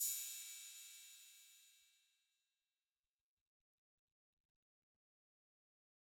Open Hat [2].wav